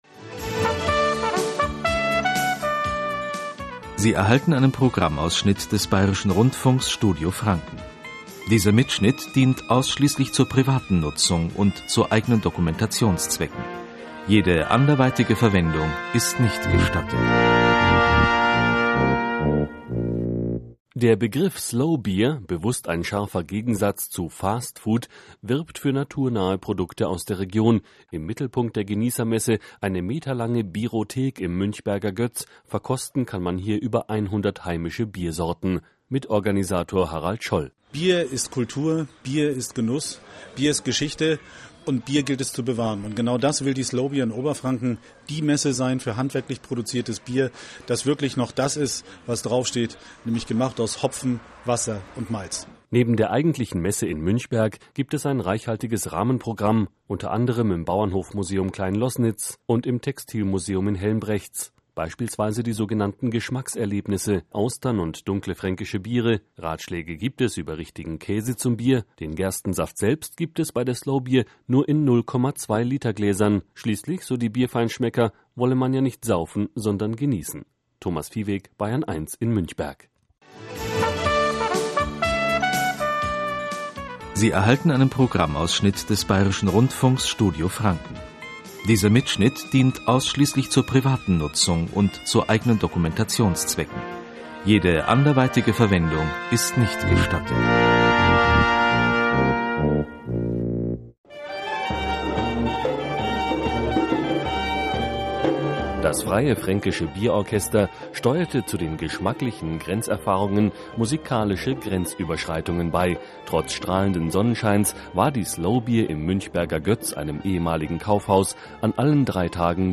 Radiobeitrag 2007